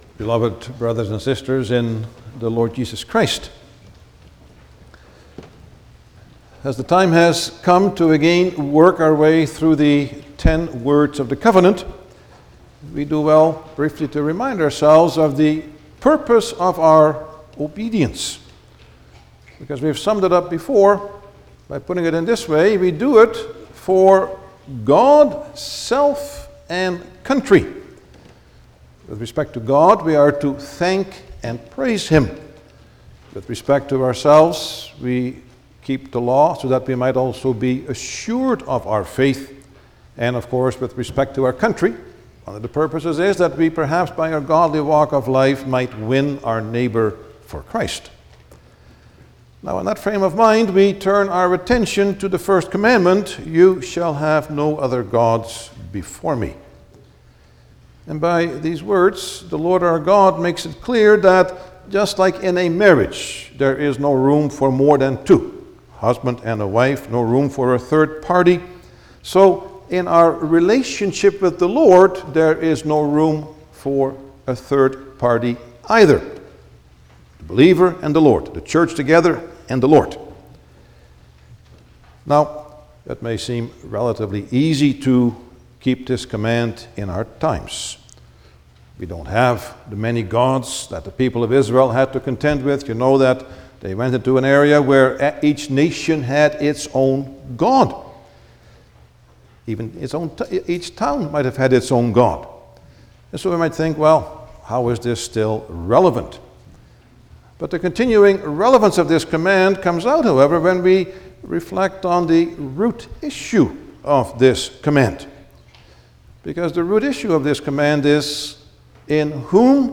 Service Type: Sunday afternoon
08-Sermon.mp3